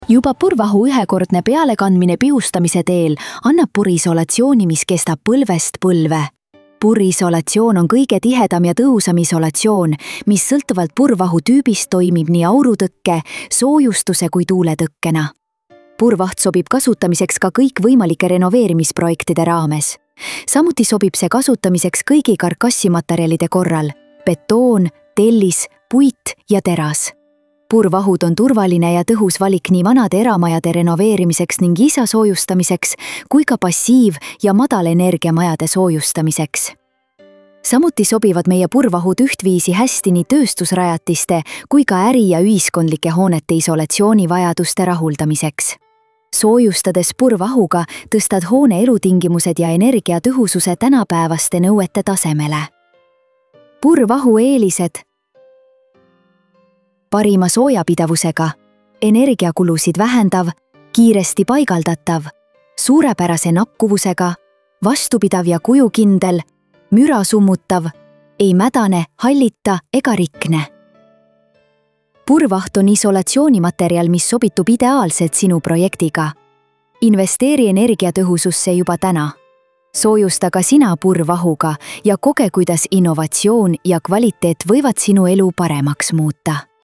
pur-vaht-text-to-speech-1.mp3